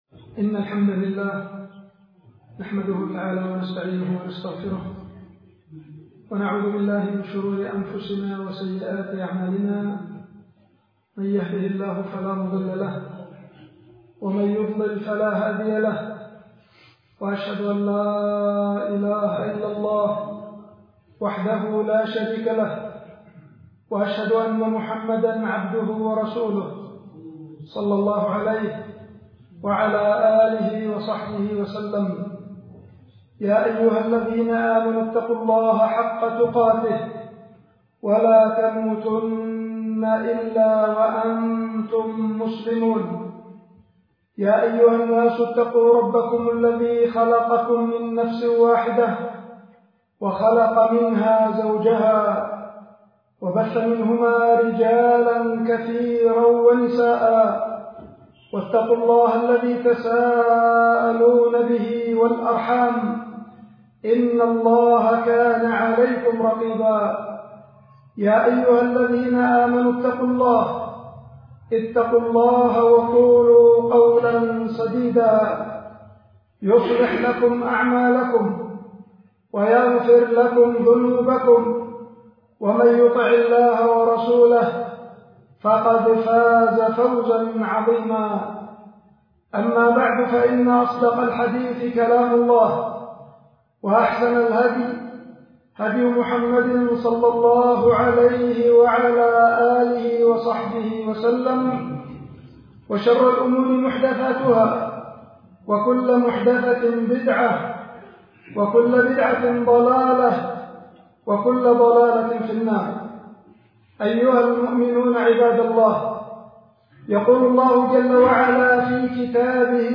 خطبة
ألقيت في مسجد جدابة بعدان – إب-اليمن